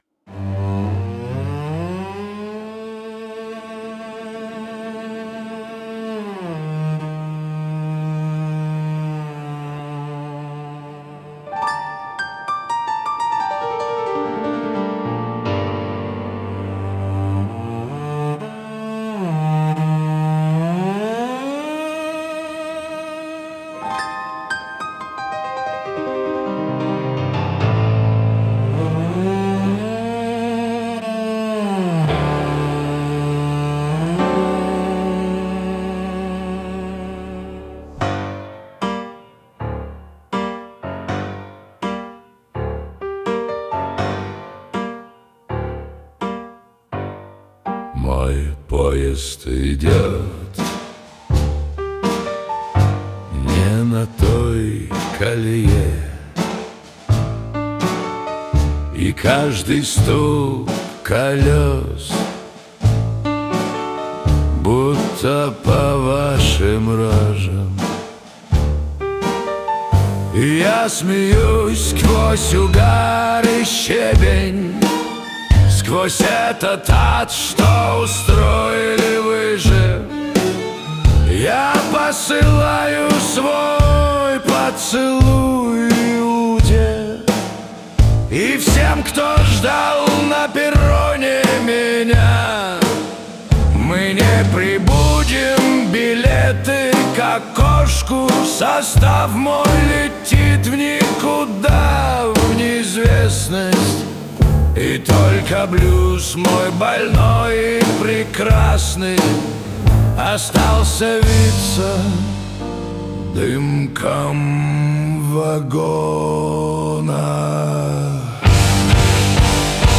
• Исполняет: Поставторcкий арт
Аристократическое кабаре-симфония-шансон.
Нервный, надрывный блюз, одетый в строгий фрак симфонической аранжировки.